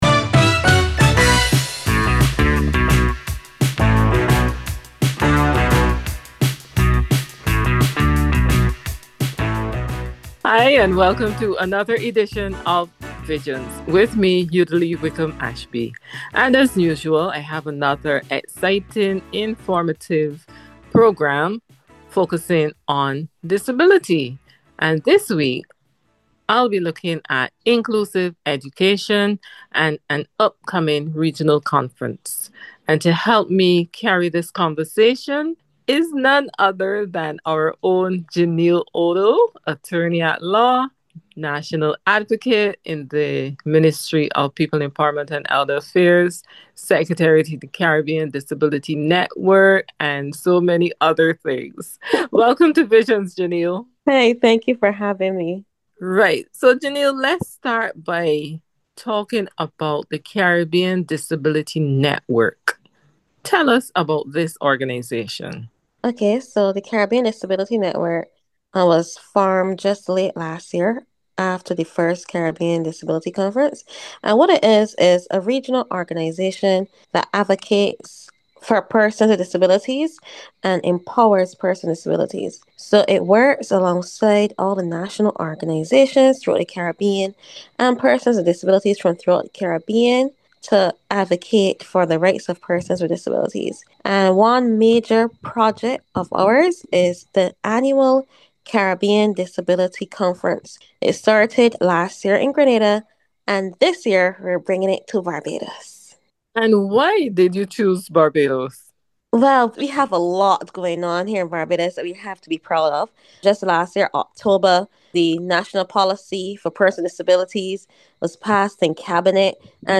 Interview with&nbsp